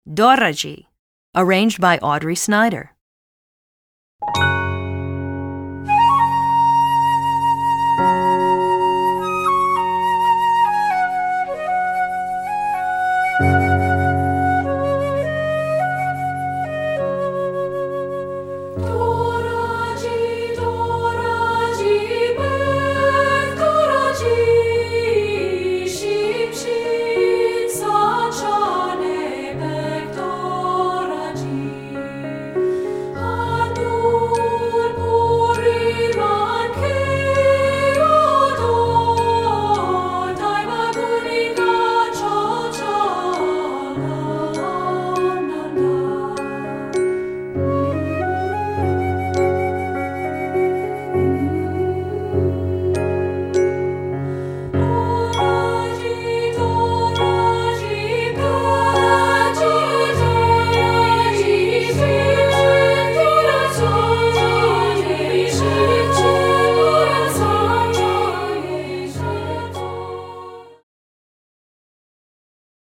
Composer: Traditional Korean
Voicing: 3-Part